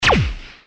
SFX激光发射音效下载
这是一个免费素材，欢迎下载；音效素材为激光发射音效， 格式为 mp3，大小1 MB，源文件无水印干扰，欢迎使用国外素材网。